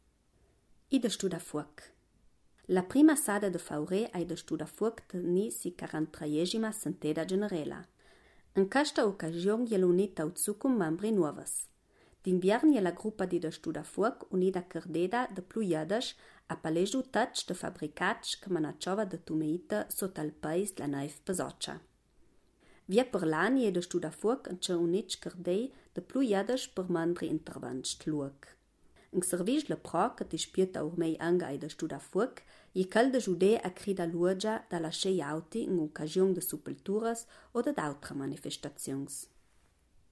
Ladino gardenese